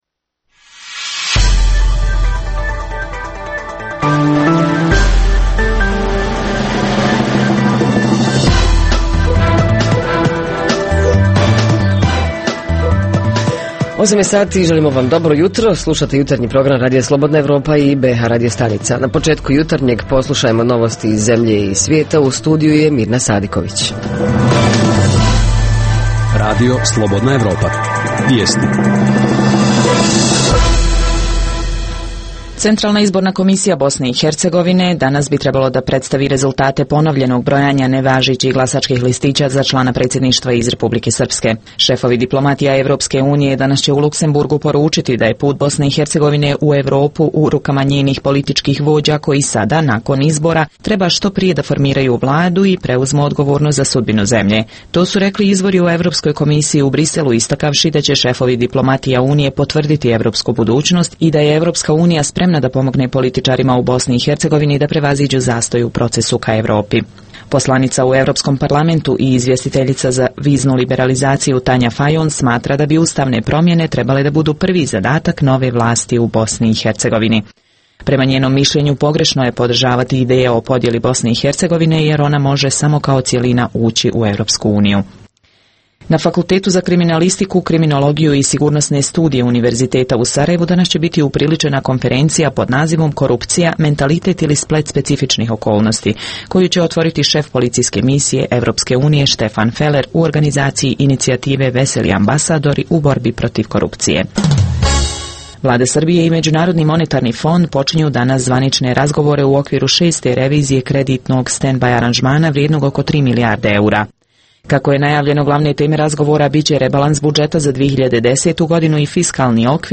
Jutarnji program za BiH koji se emituje uživo. Ponedjeljkom govorimo o najaktuelnijim i najzanimljivijim događajima proteklog vikenda.
Redovni sadržaji jutarnjeg programa za BiH su i vijesti i muzika.